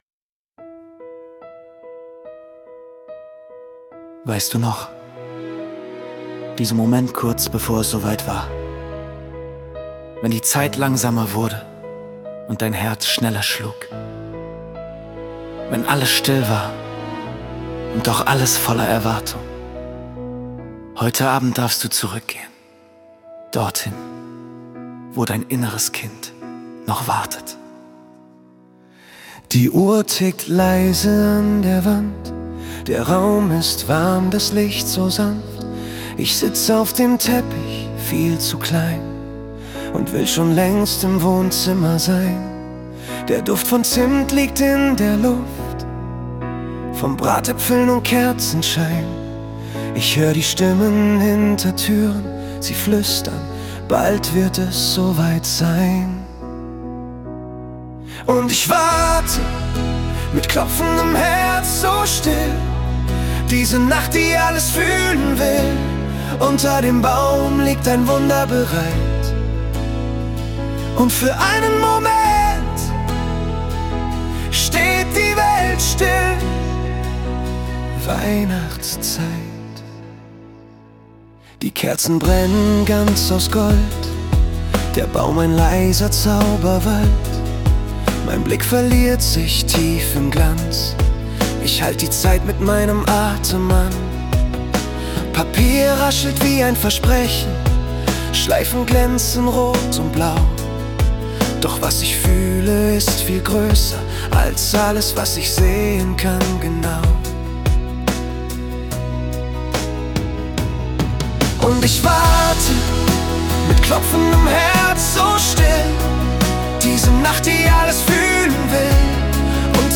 Dieses Lied ist bewusst ruhig gehalten.